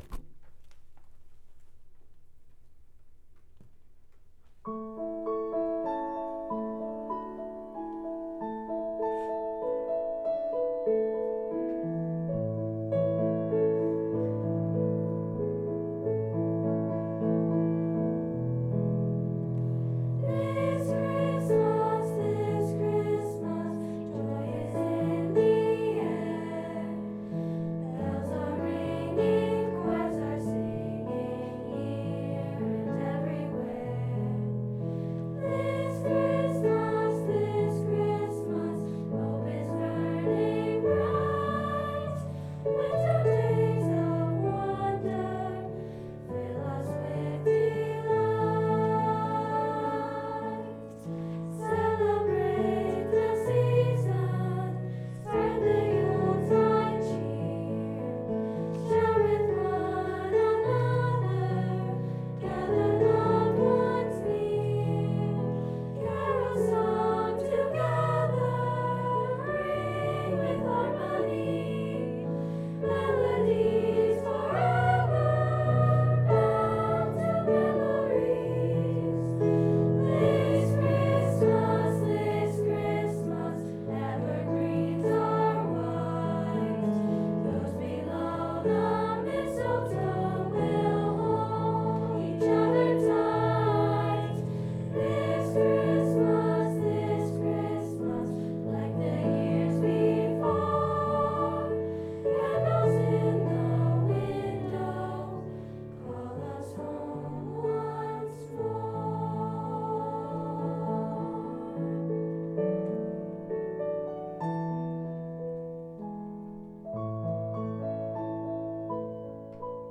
an all-female auditioned chorus